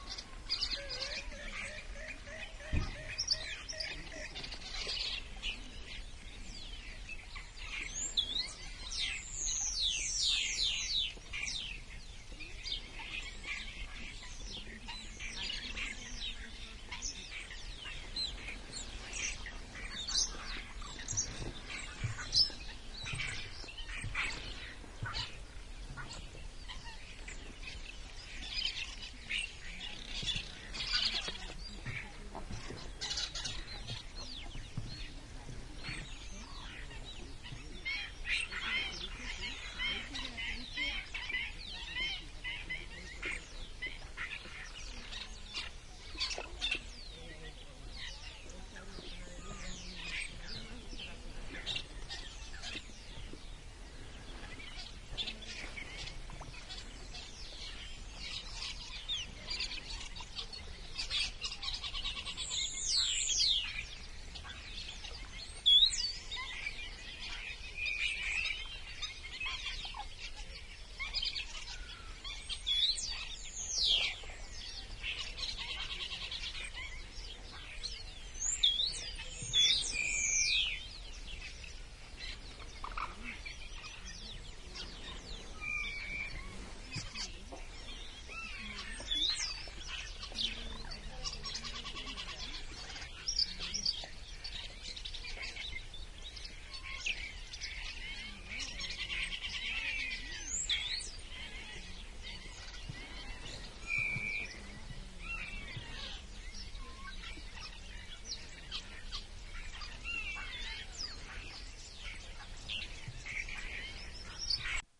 描述：乡间晴朗的早晨的声音，包括鸟叫、远处的车辆、牛铃声等
标签： 氛围 场 - 记录 自然
声道立体声